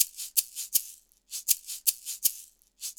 Shaker 05.wav